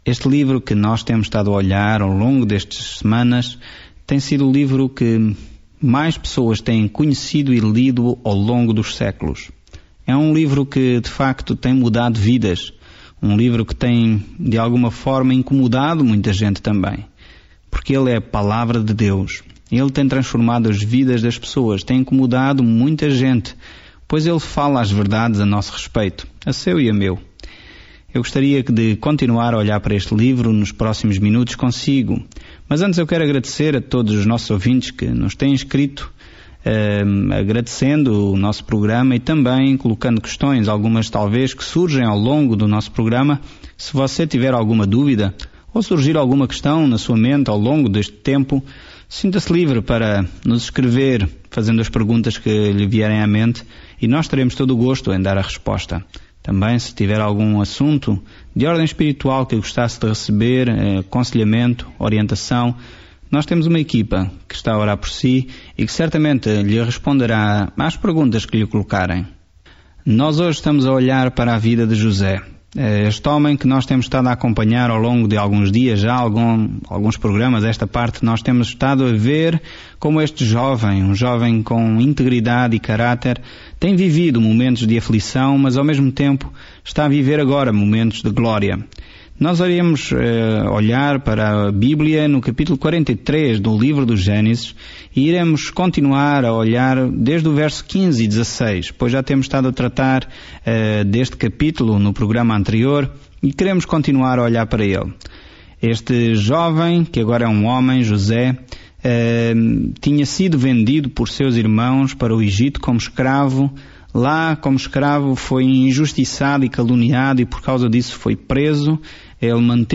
Viaje diariamente por Gênesis enquanto ouve o estudo em áudio e lê versículos selecionados da palavra de Deus no livro de Gênesis.